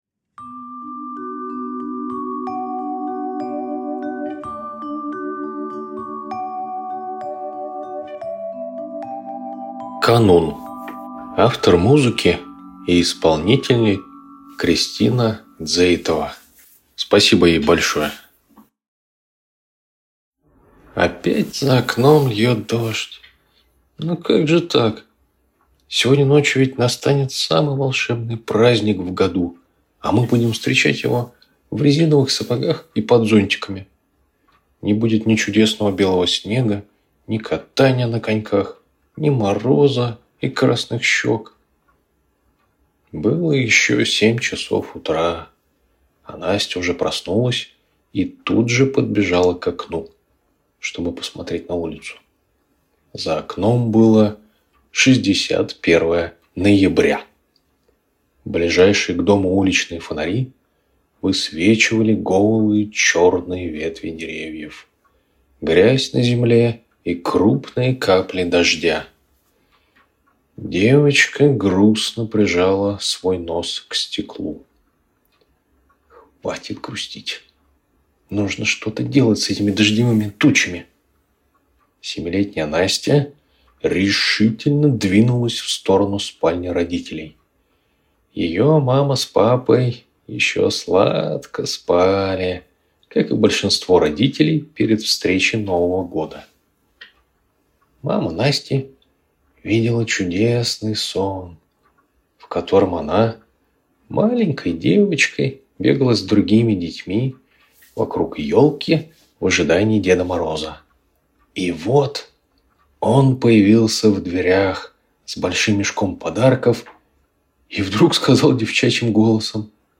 Канун - аудиосказка Ромеля - слушать онлайн